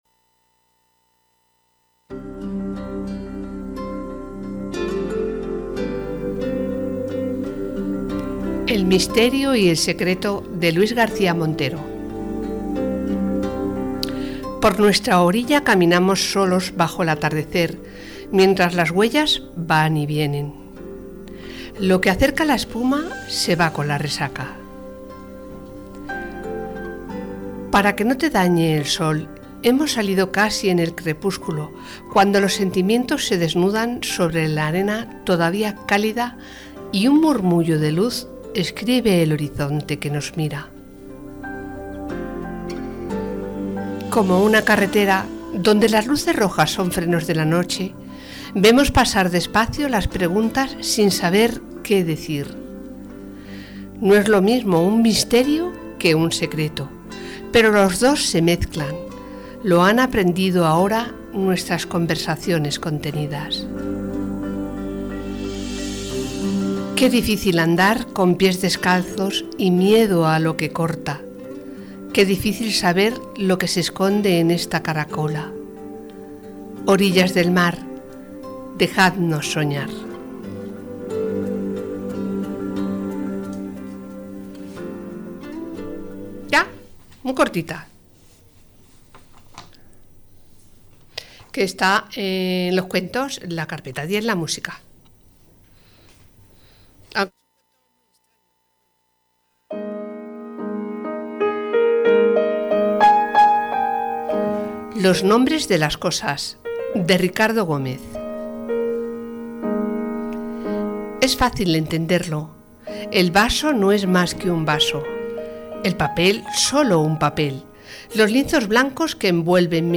desgrana con su estilo cercano la esencia berlanguiana del cuento